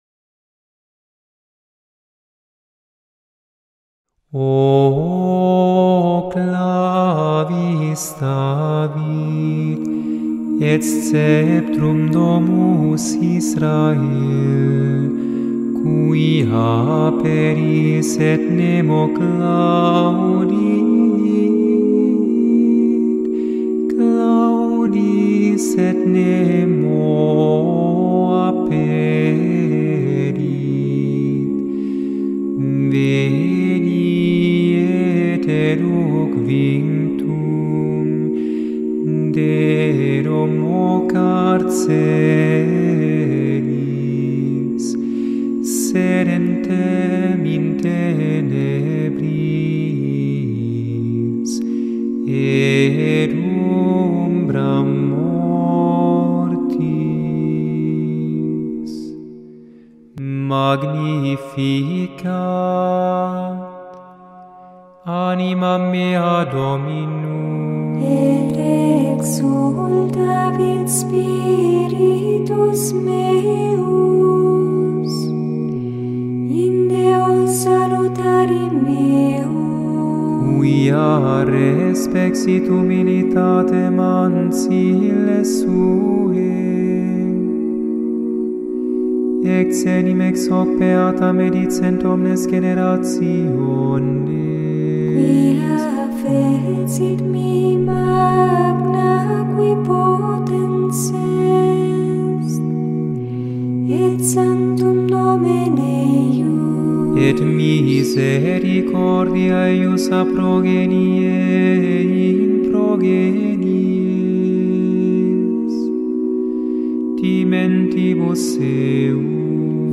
• Chaque jour, du 17 au 23 décembre (soir), on chante une antienne « Ô » dédiée à un Nom divin du Christ, tirée des prophéties de l’Ancien Testament.
Et voici des manifique enregistrement de Harpa Dei sur YouTube :
20-dec-O-CLAVIS-DAVID-O-Antiphon-and-Magnificat.mp3